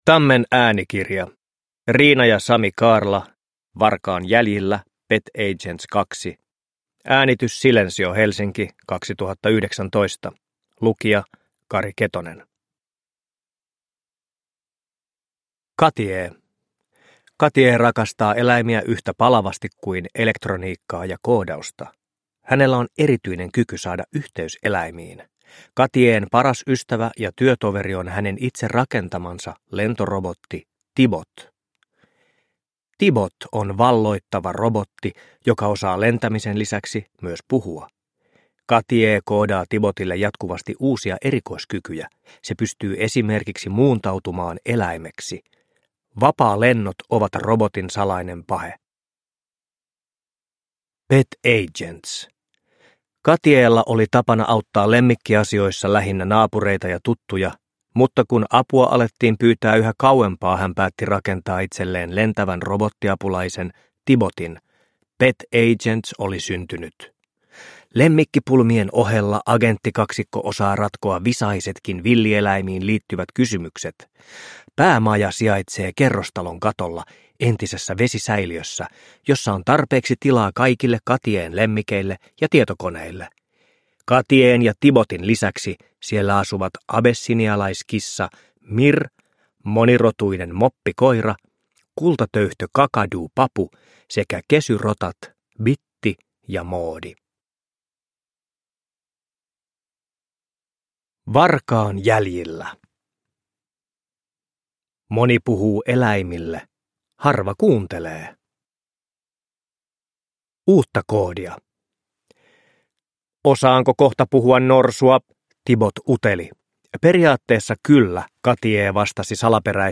Varkaan jäljillä. Pet Agents 2 – Ljudbok – Laddas ner